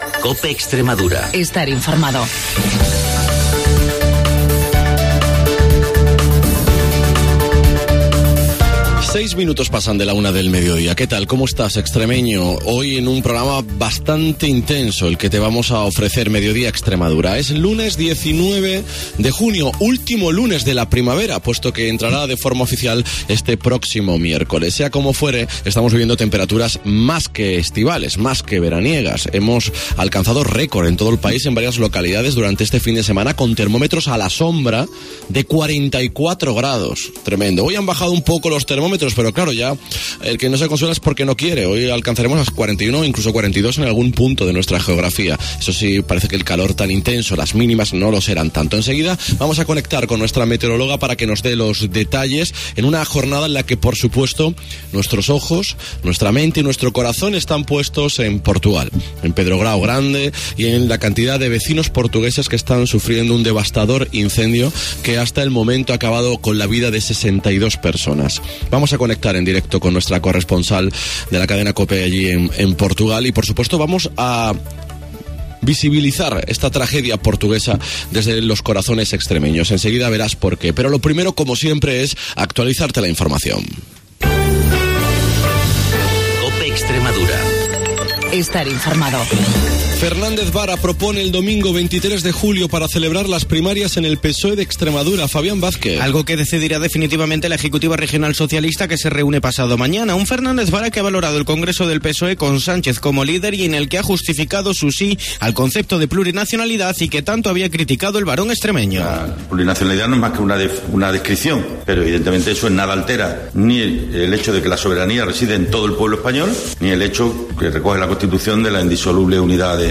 El programa líder de la radio extremeña